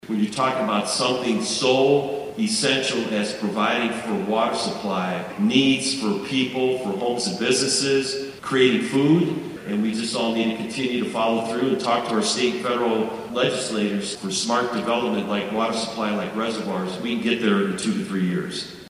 spoke to a gathering at Tuesday’s conference in Manhattan